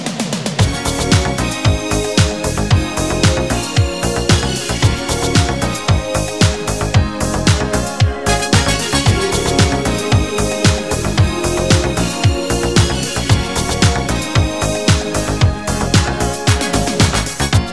• Качество: 320, Stereo
спокойные
soul
80-е
ретро